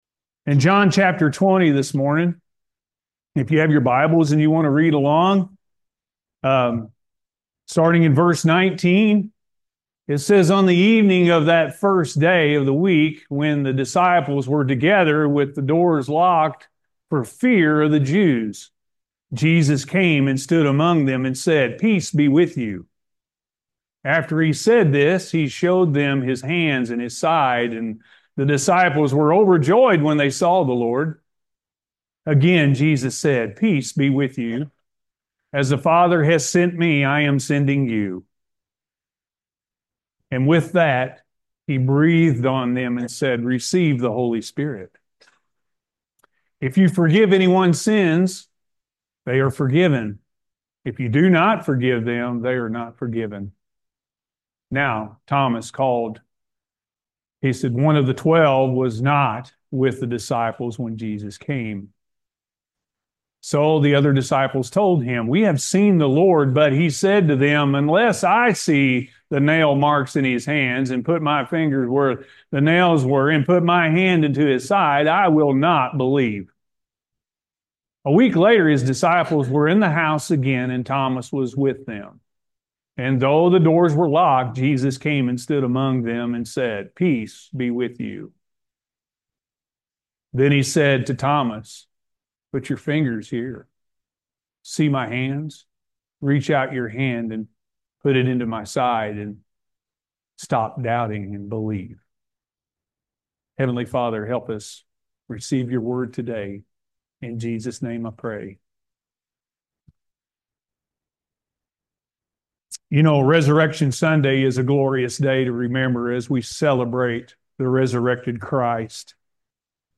A True Life Changing Encounter With Jesus-A.M. Service